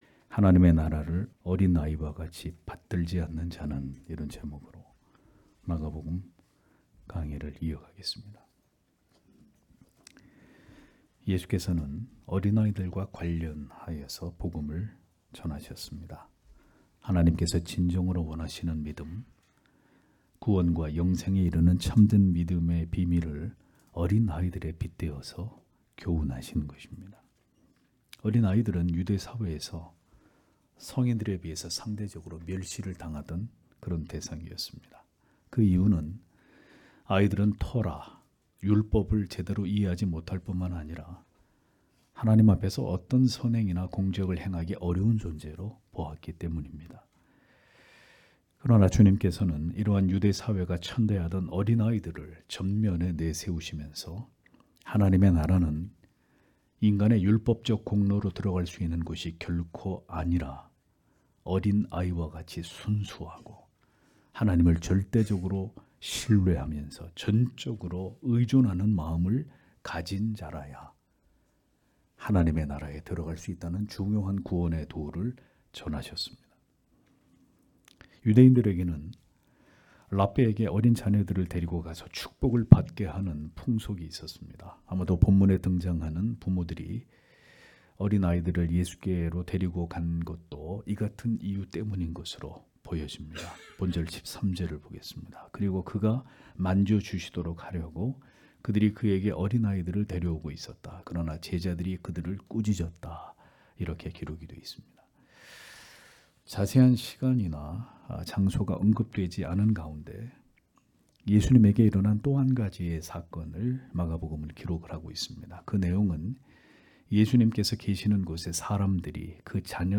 주일오전예배 - [마가복음 강해 40] 하나님의 나라를 어린아이와 같이 받들지 않는 자는 (막 10장13-16절)